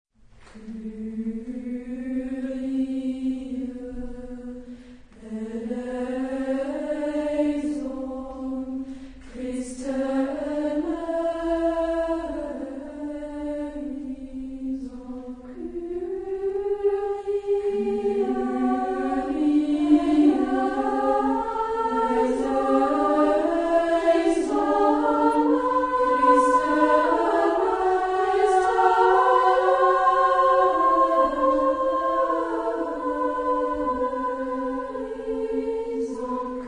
Genre-Style-Forme : Sacré ; Messe ; contemporain
Type de choeur : SMA  (6 voix égales de femmes )
Solistes : Soprano (1)